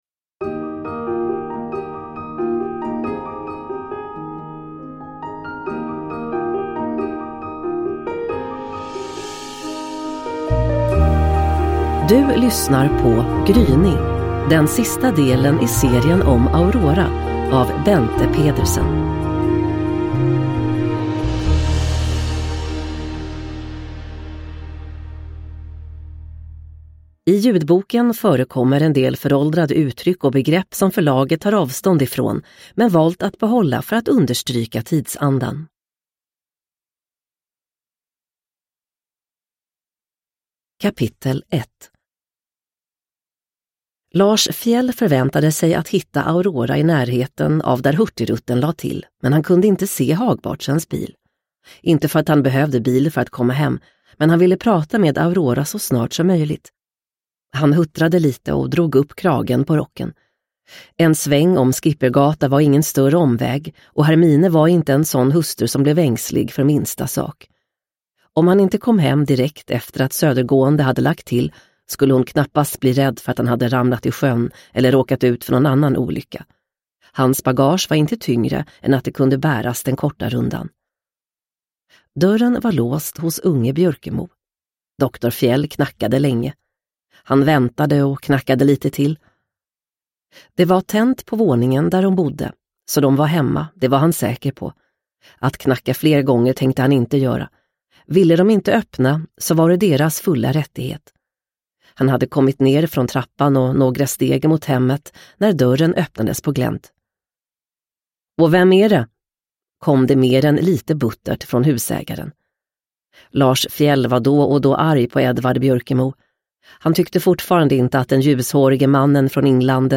Gryning – Ljudbok – Laddas ner